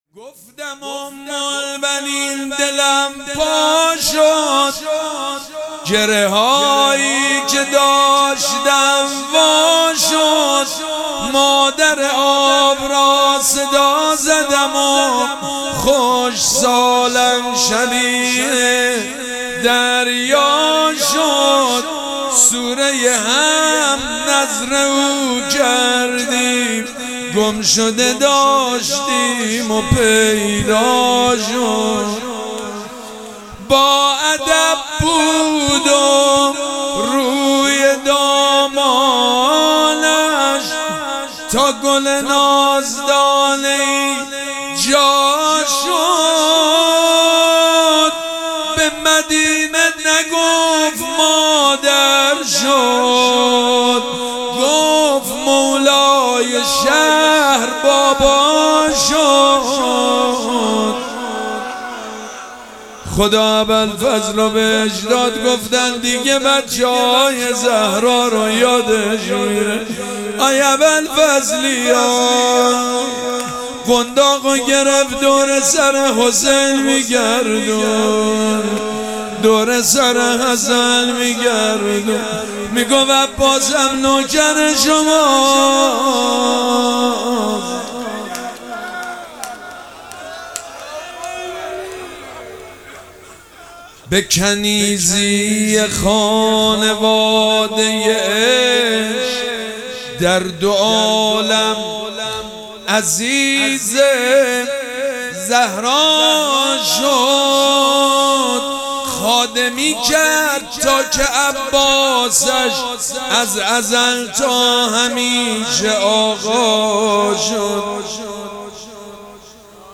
شب چهارم مراسم عزاداری اربعین حسینی ۱۴۴۷
مدح
حاج سید مجید بنی فاطمه